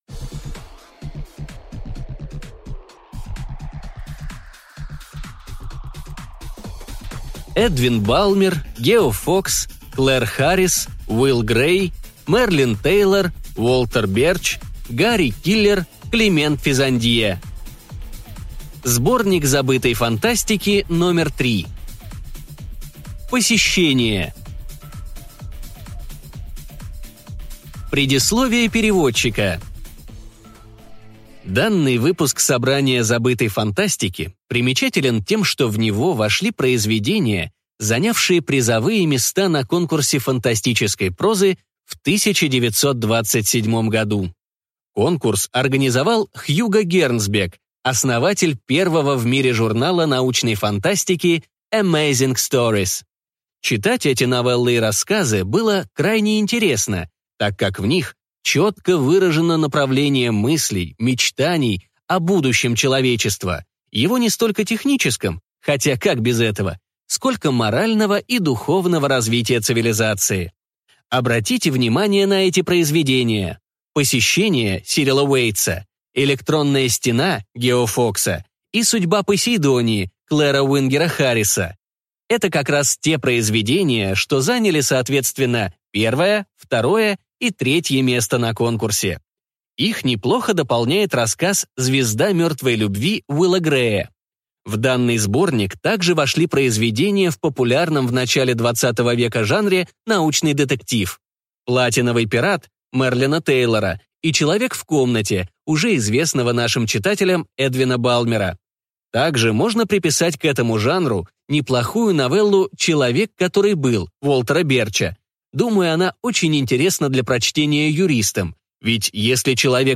Аудиокнига Сборник Забытой Фантастики №3 Посещение | Библиотека аудиокниг